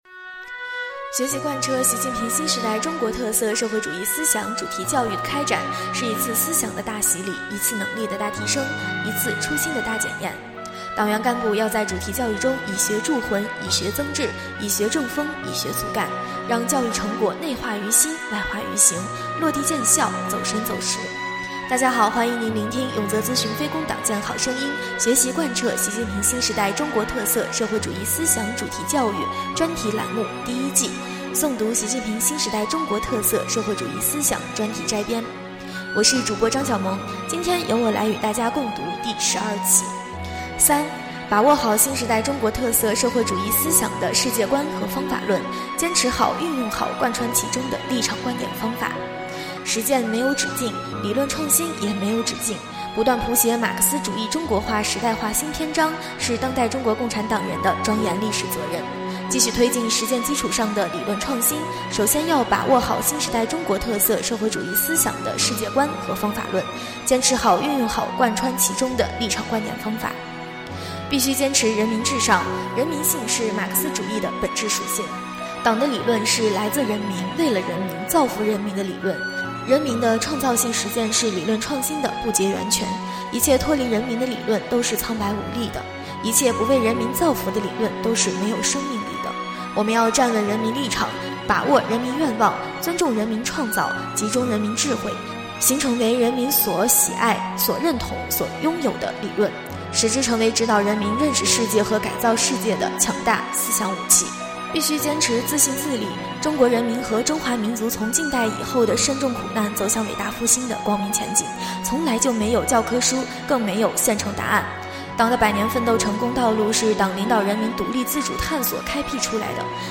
【诵读】《习近平新时代中国特色社会主义思想专题摘编》第12期-永泽党建